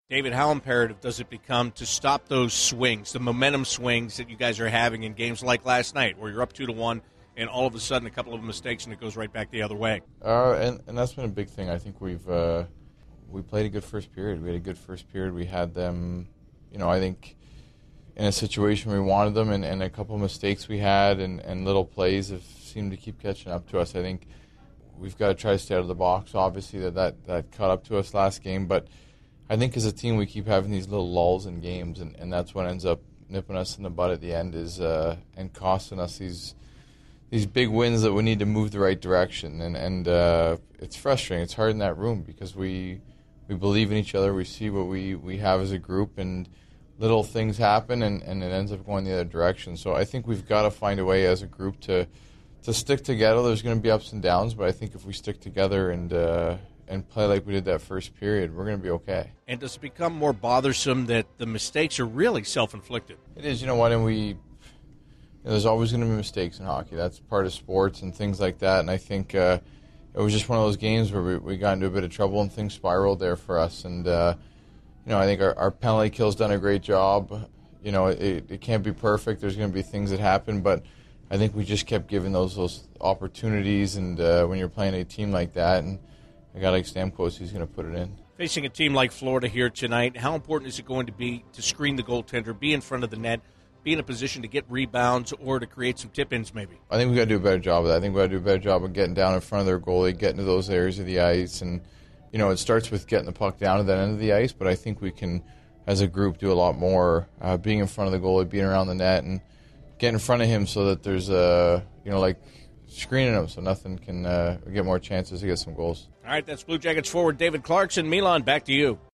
CBJ Interviews / David Clarkson Pre-Game 12/27/15